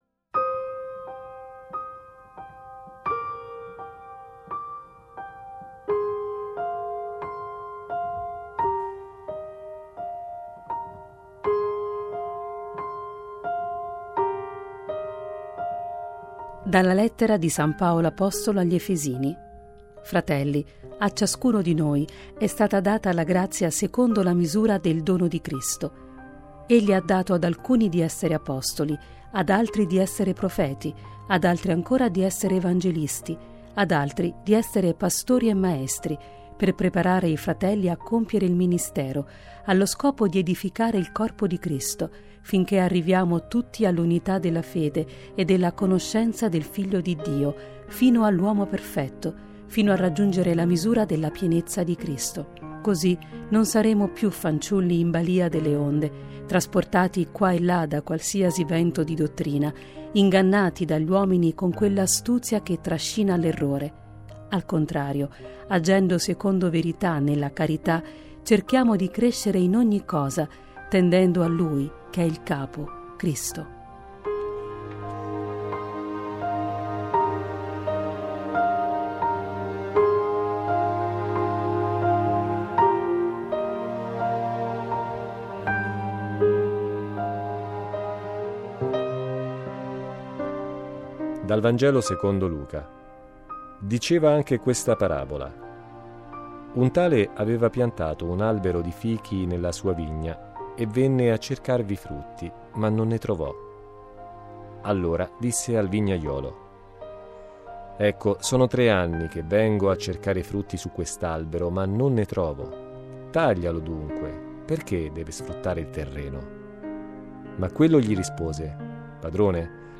Le letture del giorno (prima e Vangelo) e le parole di Papa Francesco da VaticanNews: